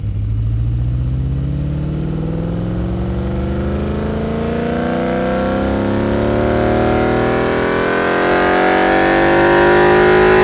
rr3-assets/files/.depot/audio/Vehicles/v8_03/v8_03_Accel.wav
v8_03_Accel.wav